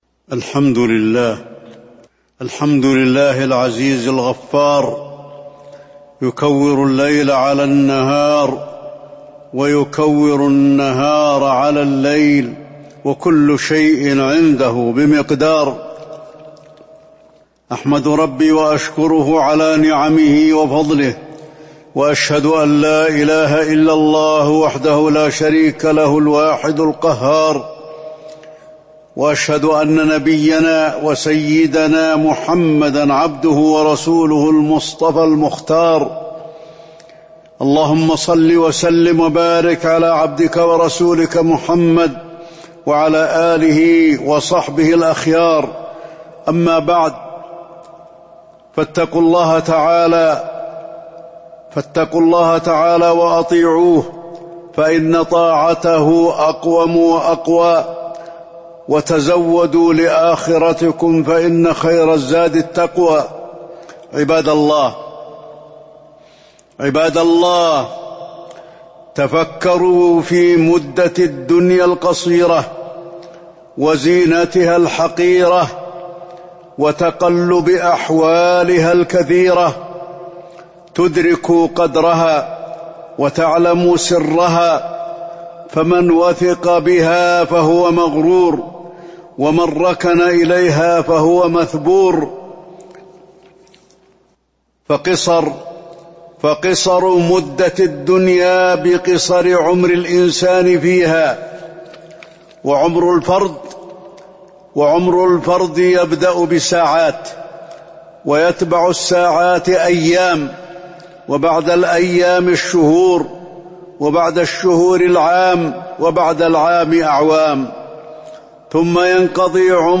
تاريخ النشر ٢٠ ذو الحجة ١٤٤٢ هـ المكان: المسجد النبوي الشيخ: فضيلة الشيخ د. علي بن عبدالرحمن الحذيفي فضيلة الشيخ د. علي بن عبدالرحمن الحذيفي الدنيا متاع الغرور